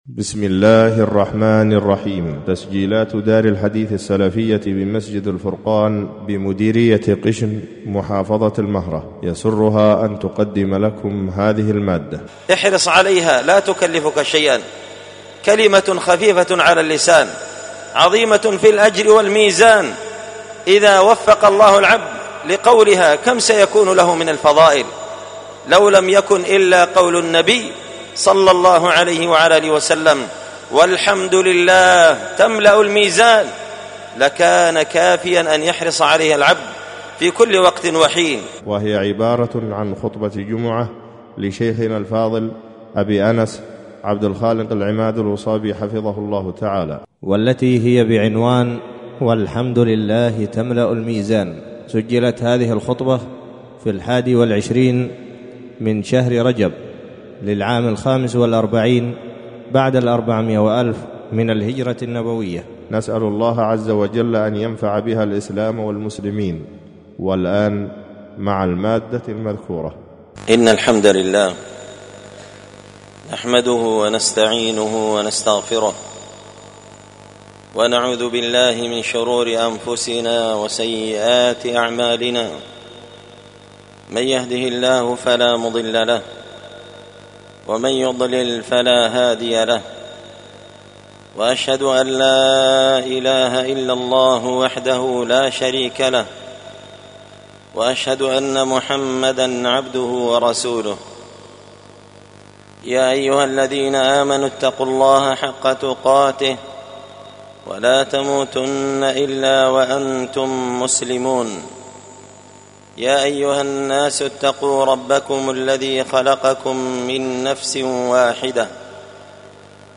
خطبة جمعة بعنوان
ألقيت هذه الخطبة بدار الحديث السلفية بمسجد الفرقان قشن-المهرة-اليمن تحميل…